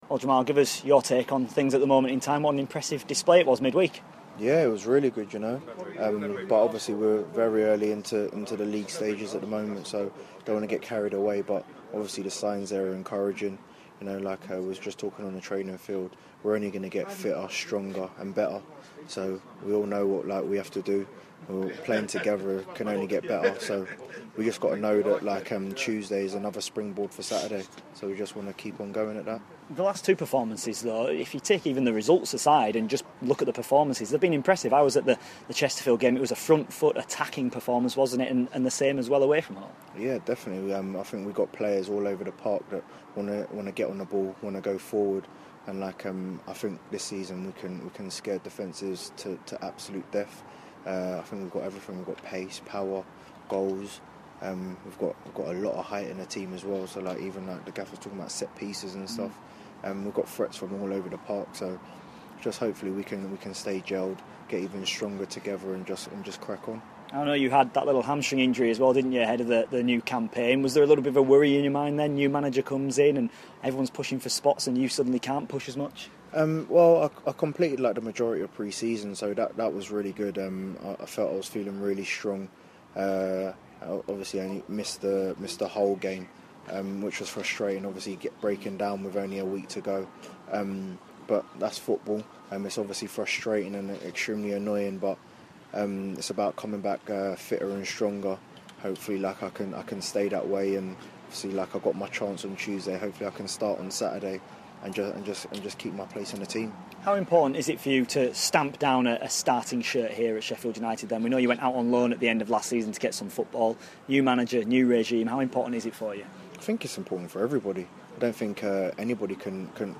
INTERVIEW: Sheffield United winger Jamal Campbell-Ryce ahead of the Blades game with Blackpool